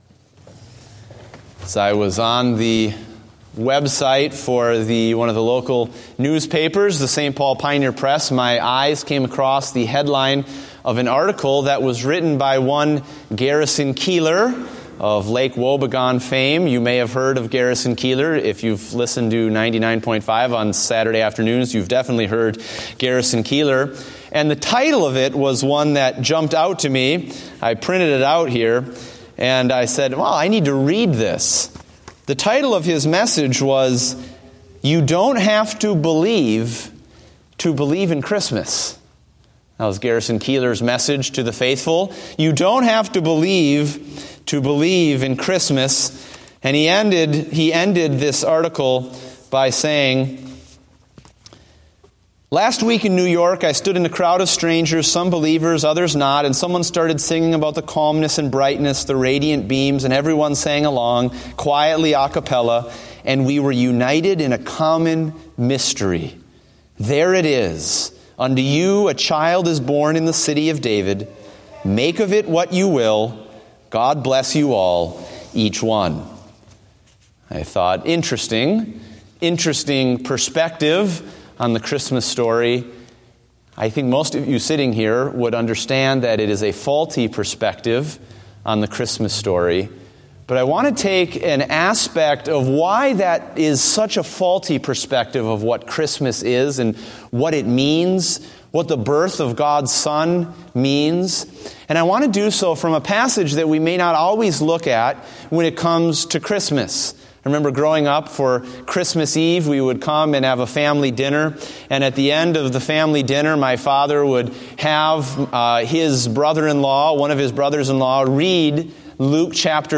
Series: Christmas Eve Service